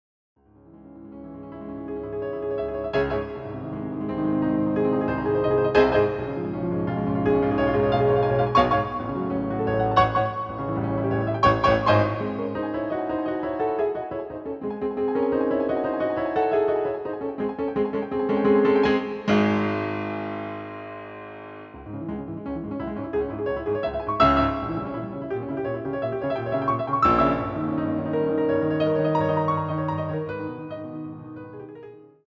CD quality digital audio Mp3 file from a Piano Roll